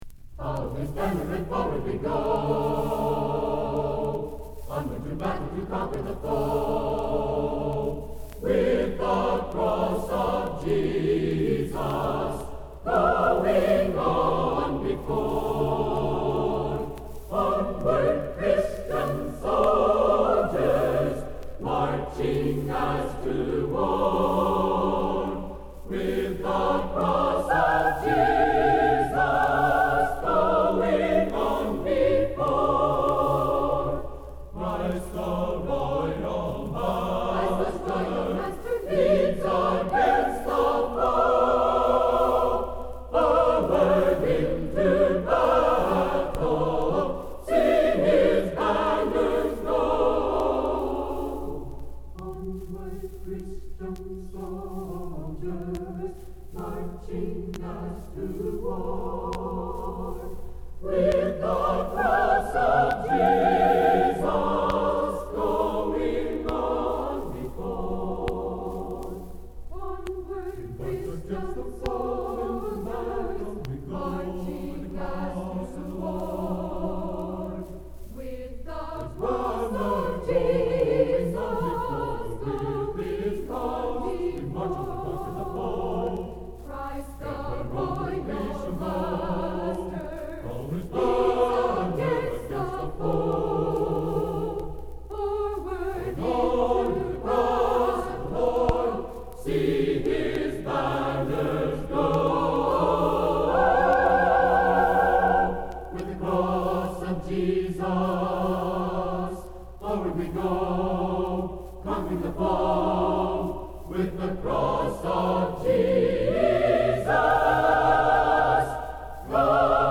Bethany Nazarene College A Cappella Choir Sings - 50th Anniversary Choir at Pilot Point, Texas
at the 50th Anniversary celebration of the founding of the Church of the Nazarene held at Pilot, Texas, October 13, 1958.